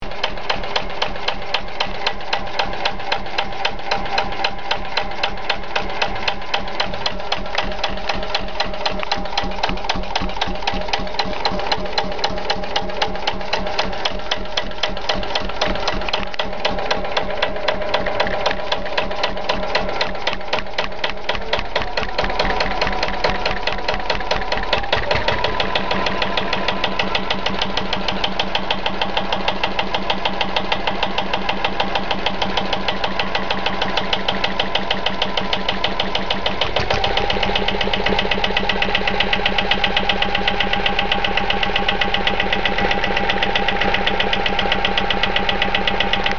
MVI 0208 | Dit moesten eigenlijk filmpjes zijn, maar op het moment dat ik wou filmen hield het beeld er mee op. geluid heeft hij wel opgenomen en dan is nu juist het mooiste :-) Oorspronkelijke foto Totaal aantal foto's: 49 | Help